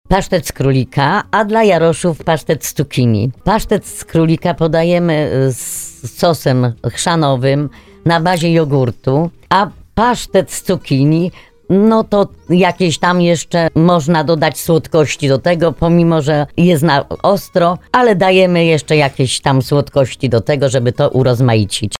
Koło Gospodyń Wiejskich w Biadolinach Szlacheckich gościło na antenie Radia RDN w ramach cyklu audycji Strażniczki Małopolskich Smaków.